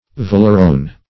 Search Result for " valerone" : The Collaborative International Dictionary of English v.0.48: Valerone \Val"er*one\, n. (Chem.) A ketone of valeric acid obtained as an oily liquid.